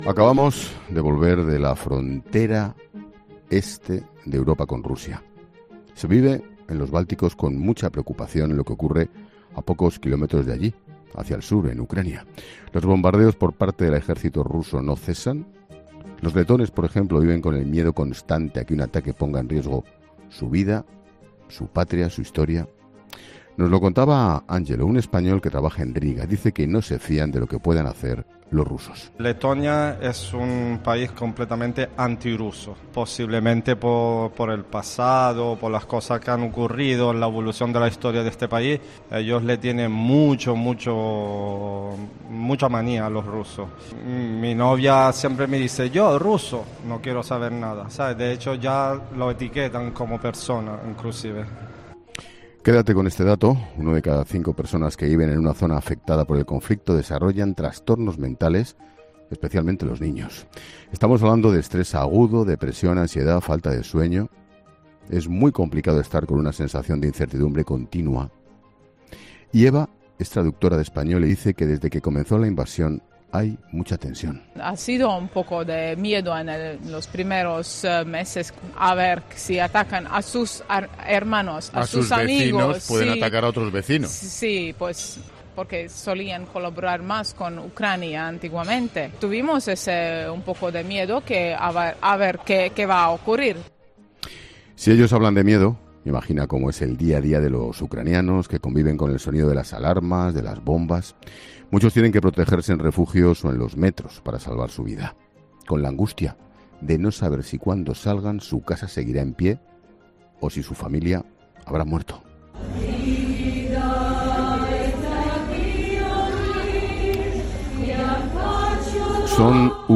La psicóloga